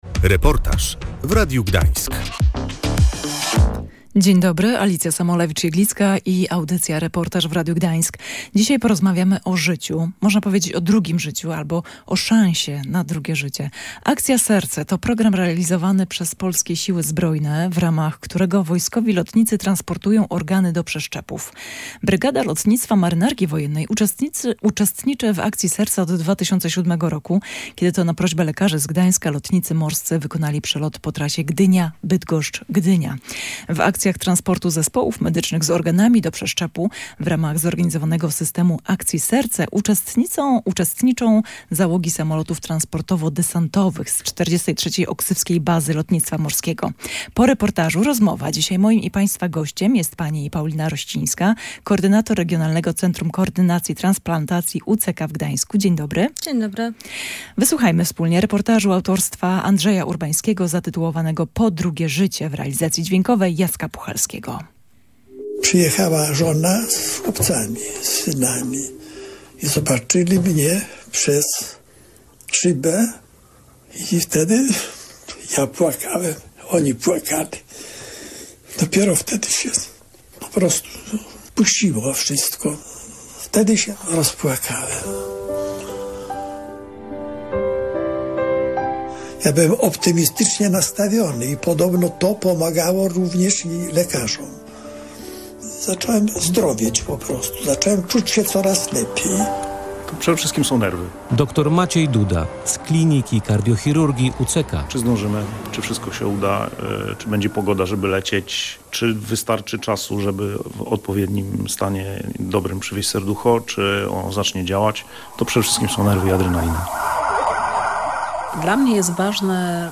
W reportażu wykorzystano fragment audycji telewizyjnej poświęconej prof. Relidze i transplantacji.